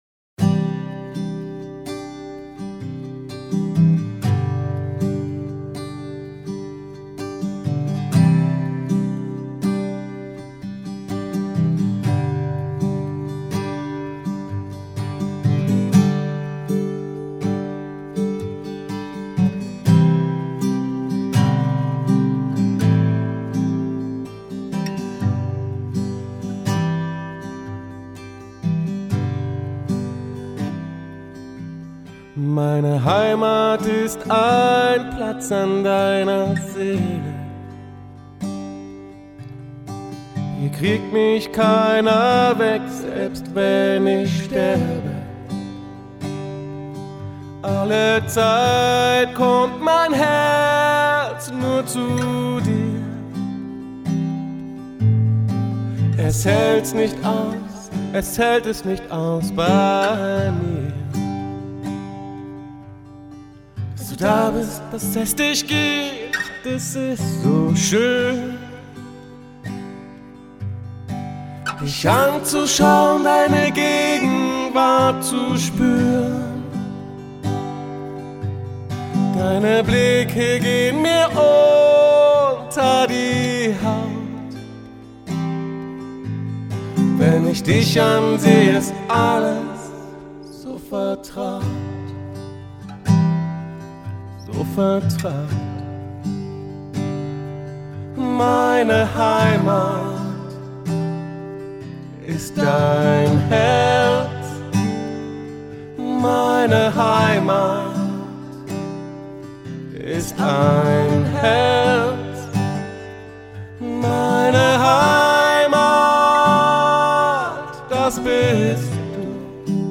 Es ist nur Gitarre und Gesang und mich würde interessieren was Ihr davon haltet.
Es ist ein einfacher Song mit Wald und Wiesen Akkorden. Dieses Liedchen hab ich mit na alten Logic Vers. 5.5.1 aufgenommen und einem AudioTechnika Mikrofon.